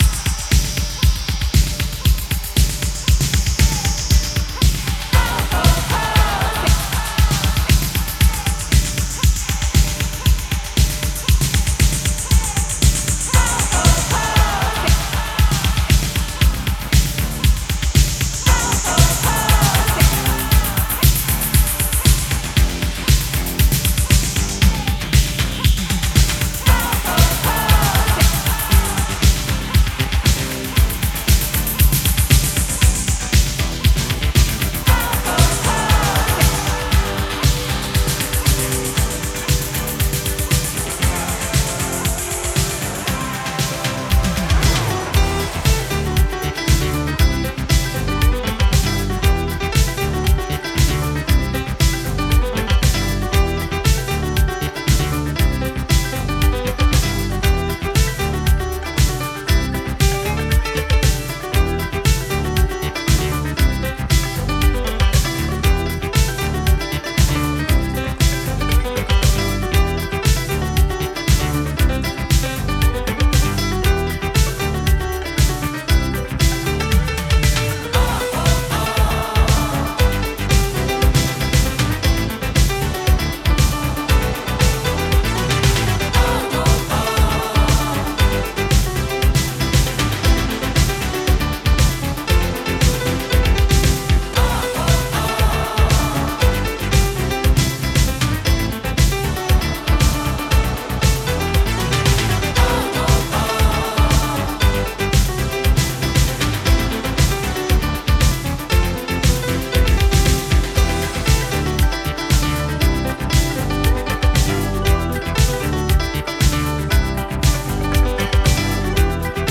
バッドトリップ寸前の過剰なエフェクトをくぐり抜けた先で多幸感を演出する